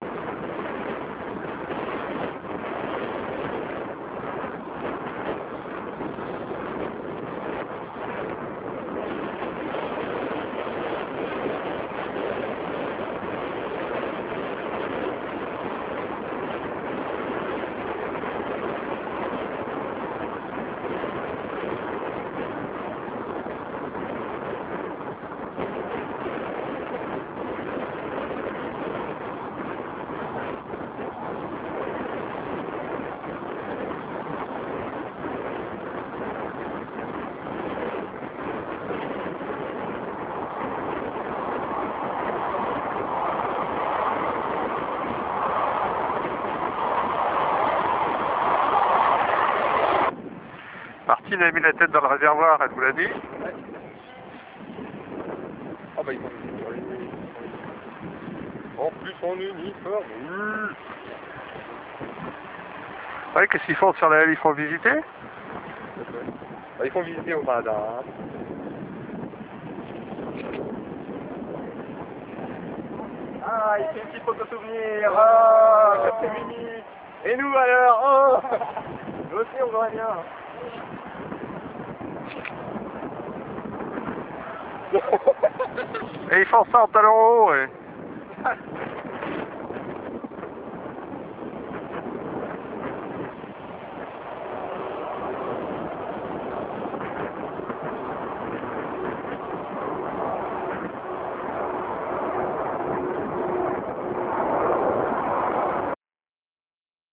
Mini vidéo de l'attérro